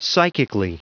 Prononciation du mot psychically en anglais (fichier audio)
psychically.wav